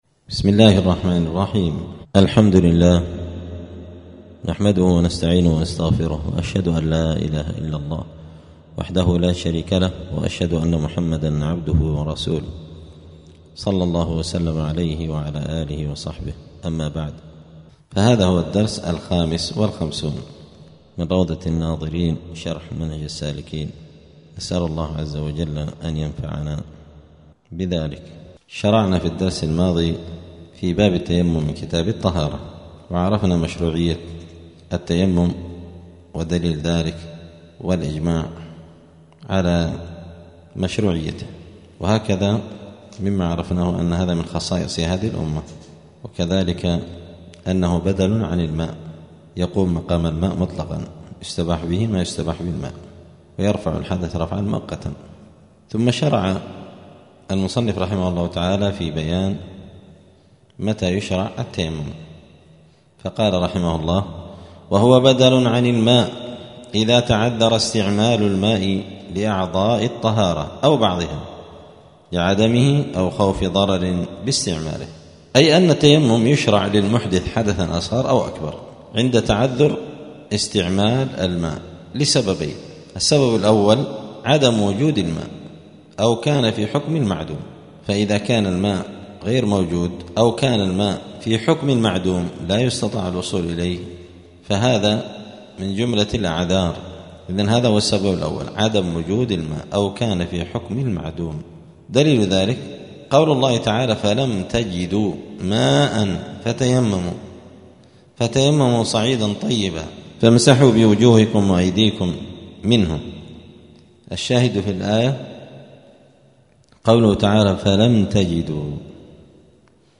*الدرس الخامس والخمسون (55) {كتاب الطهارة باب التيمم متى يشرع التيمم}*
دار الحديث السلفية بمسجد الفرقان قشن المهرة اليمن